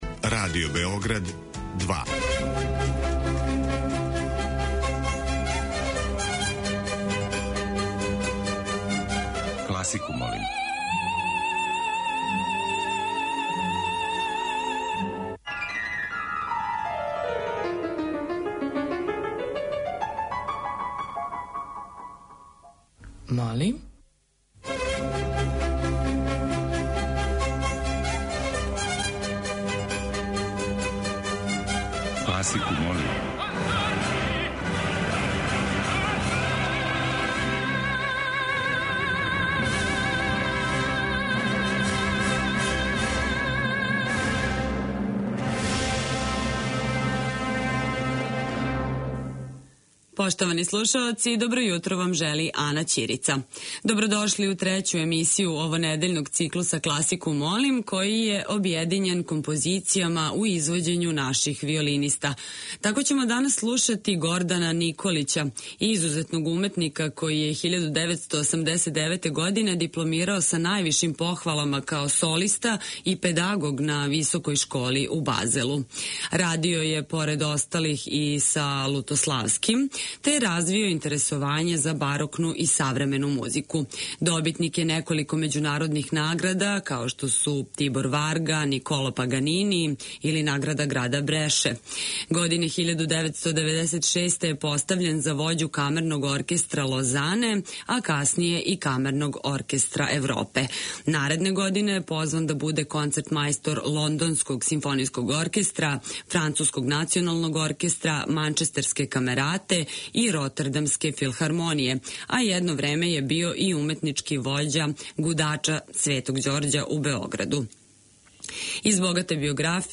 Листа предлога за хит класичне музике Радио Београда 2 ове седмице је обједињена композицијама које изводе наши виолинисти.
Уживо вођена емисија, окренута широком кругу љубитеља музике, разноврсног је садржаја, који се огледа у подједнакој заступљености свих музичких стилова, епоха и жанрова.